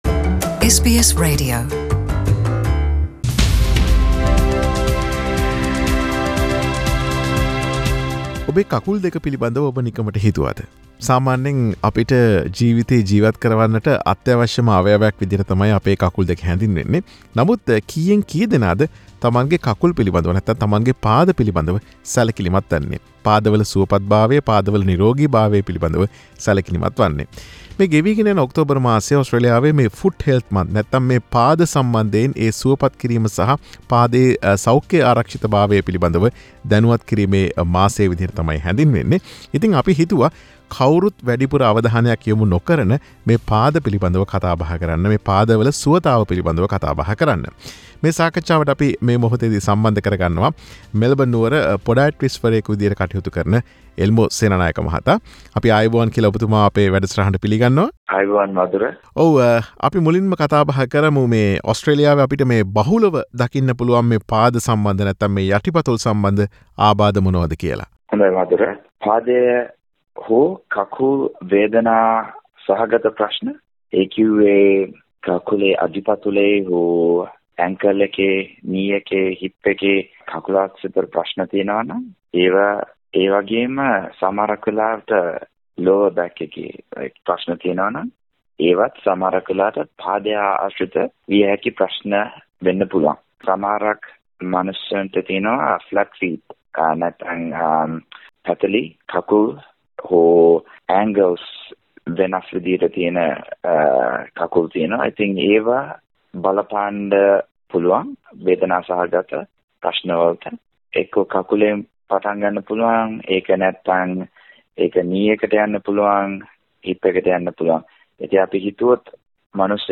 SBS සිංහල සේවය සිදු කළ සාකච්ඡාව.